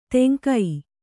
♪ teŋkai